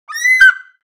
دانلود آهنگ پرنده 23 از افکت صوتی انسان و موجودات زنده
دانلود صدای پرنده 23 از ساعد نیوز با لینک مستقیم و کیفیت بالا
جلوه های صوتی